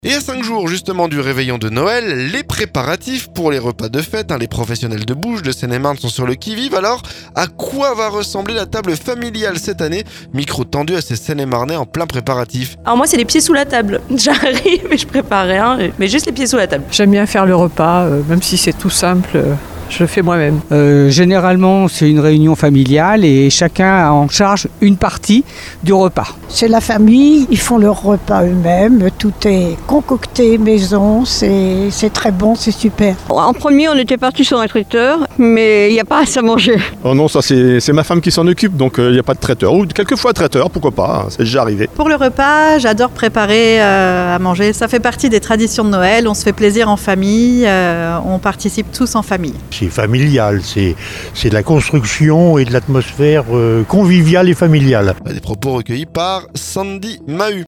A quoi va ressembler la table familiale cette année ? Micro tendu à ces Seine-et-marnais en pleins préparatifs.